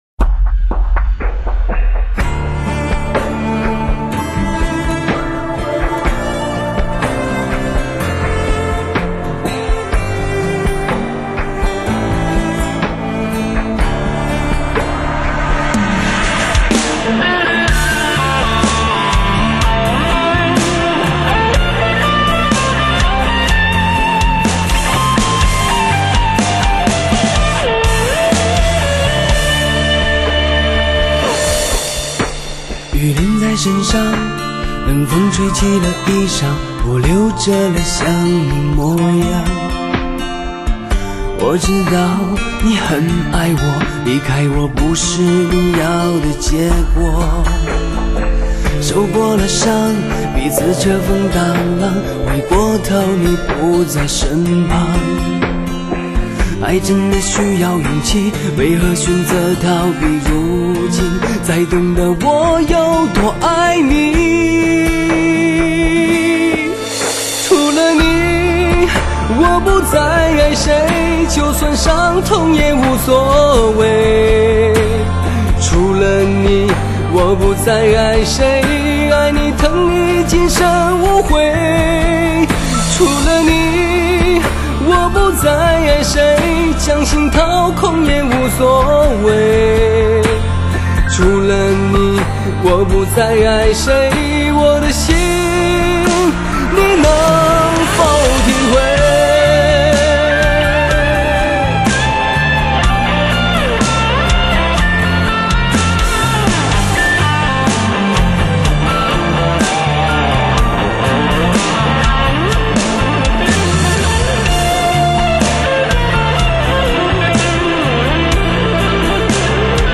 用最纯净的嗓音，描绘情归何处……